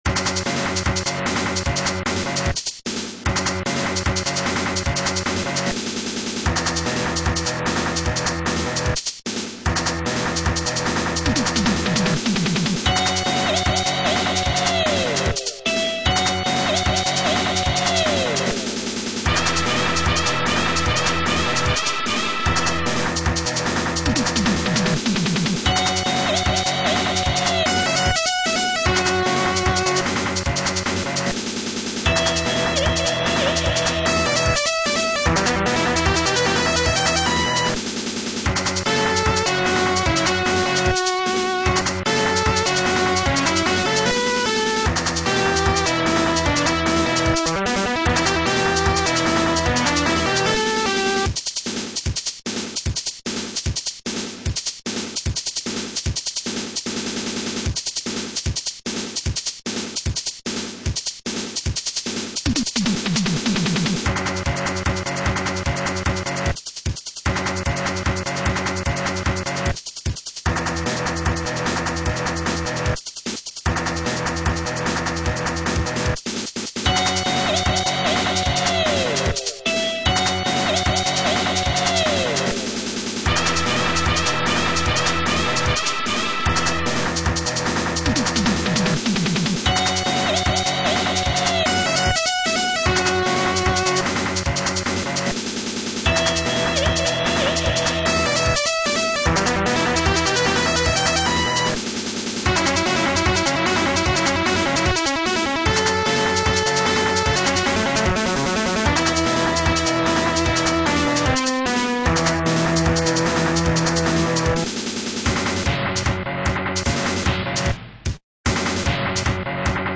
Digital title music
Features digitized title soundtrack at 11.2KHz